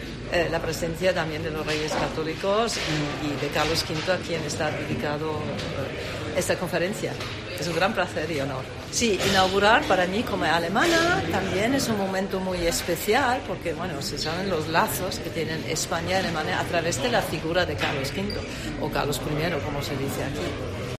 Embajadora de Alemania en España